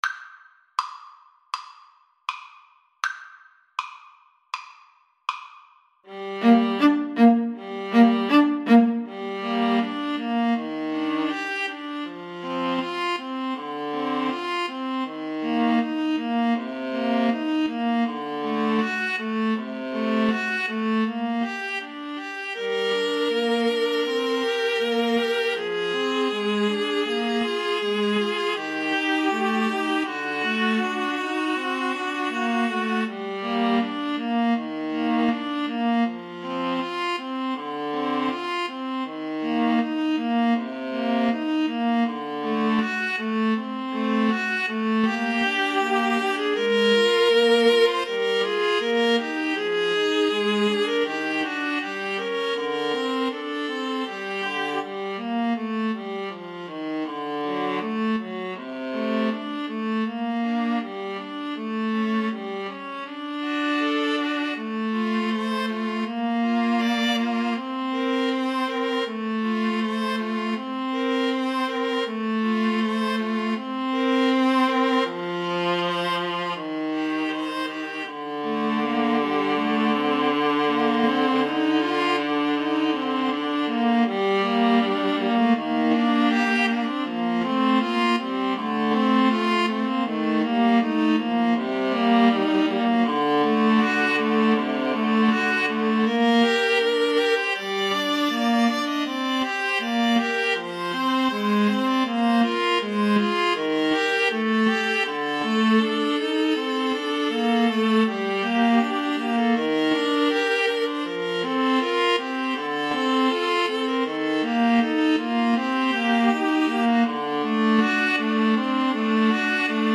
=100 Andante
Classical (View more Classical Viola Trio Music)